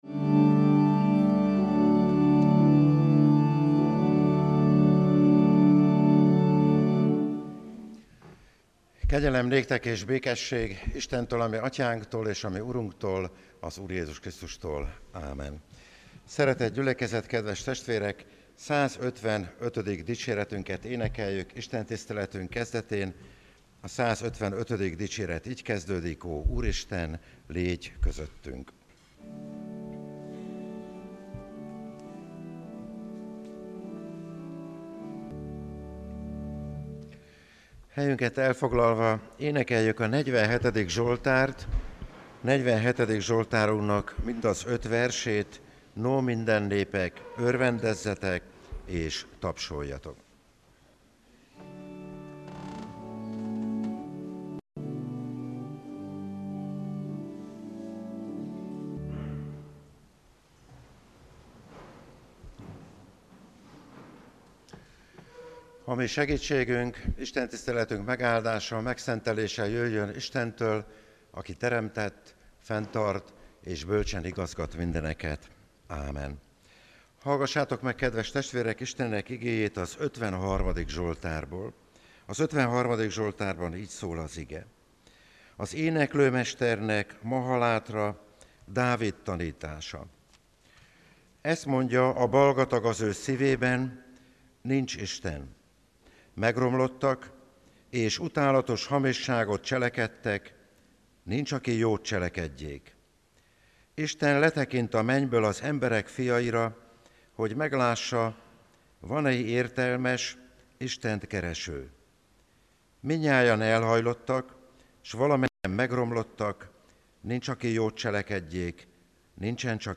– Budahegyvidéki Református Egyházközség
Lekció: 53. zsoltár Imádkozzunk!